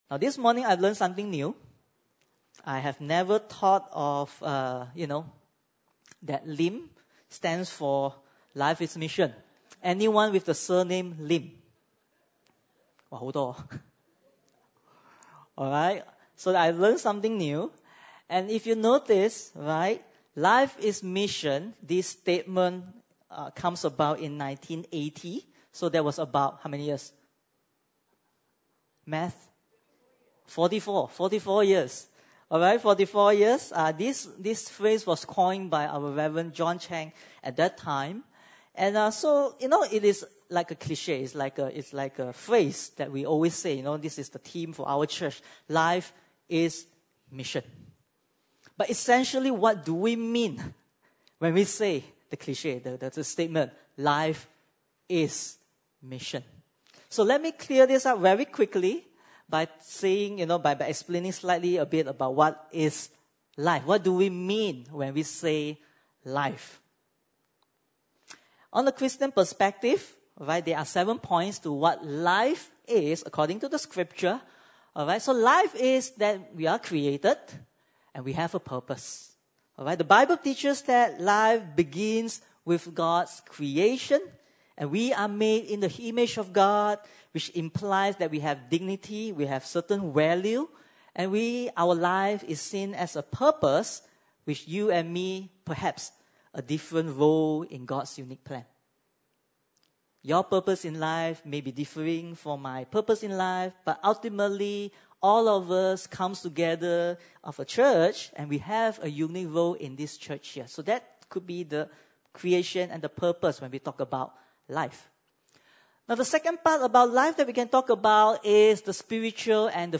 Passage: Romans 1:16 Service Type: Sunday Service (Desa ParkCity) « The King on Trial Onward Christian Soldier